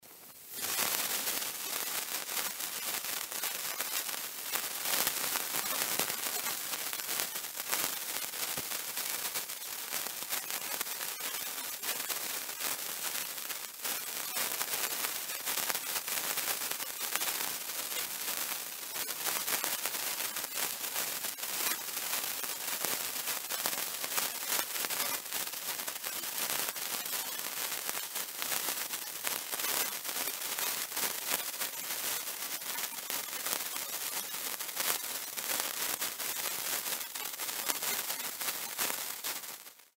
Яркое шипение, веселый треск и фейерверк искр — эти знаменитые праздничные звуки создадут волшебную атмосферу Нового года, дня рождения или другого торжества.
Спокойное горение бенгальского огня